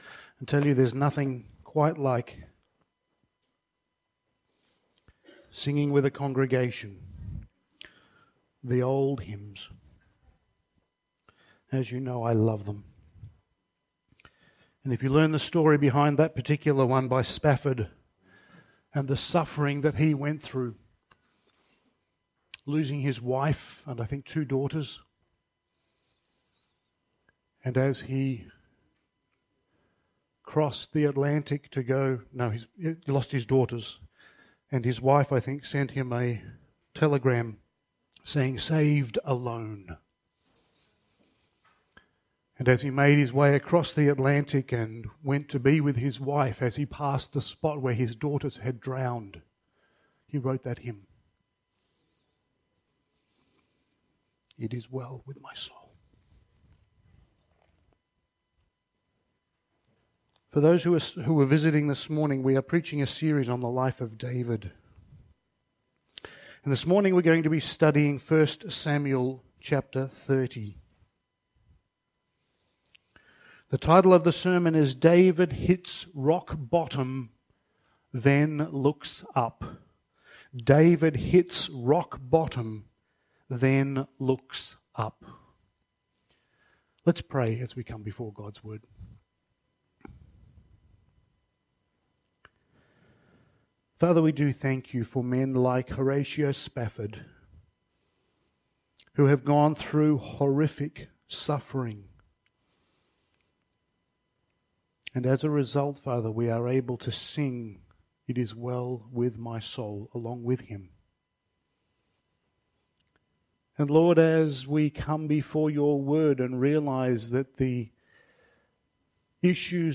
Passage: 1 Samuel 30:1-31 Service Type: Sunday Morning